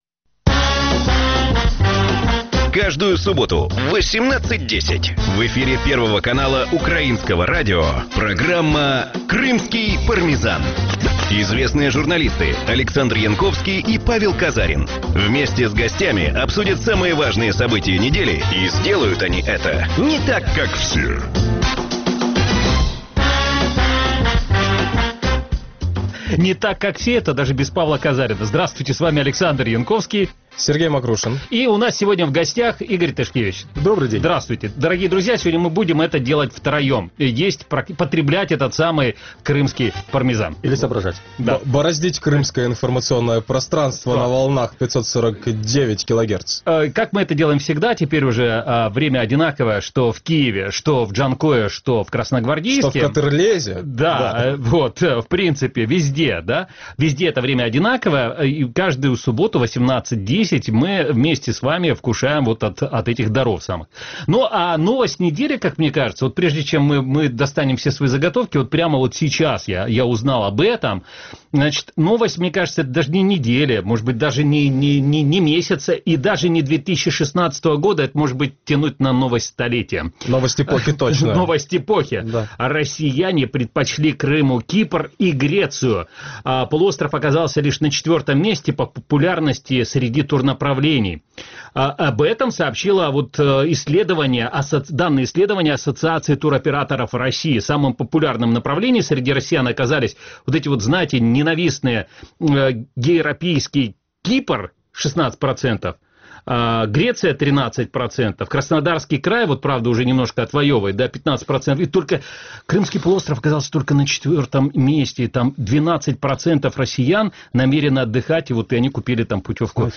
Программа звучит в эфире Радио Крым.Реалии. Это новый, особенный формат радио.